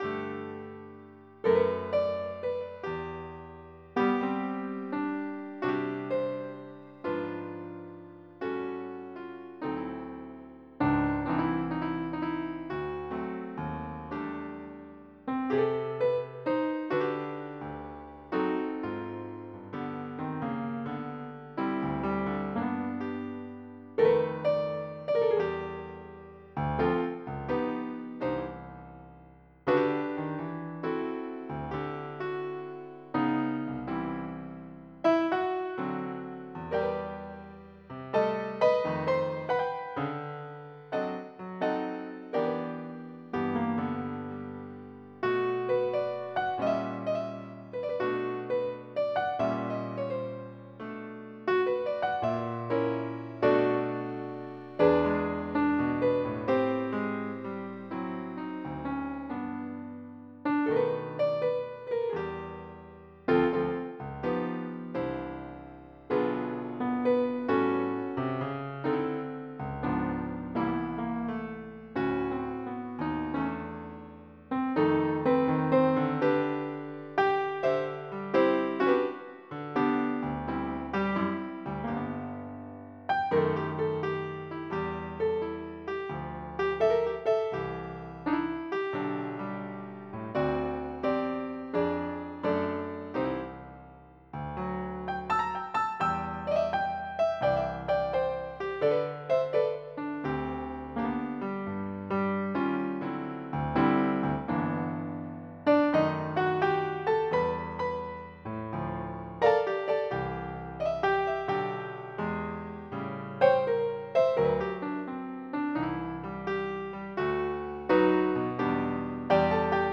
Type General MIDI